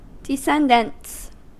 Ääntäminen
Synonyymit offspring Ääntäminen US Haettu sana löytyi näillä lähdekielillä: englanti Käännöksiä ei löytynyt valitulle kohdekielelle.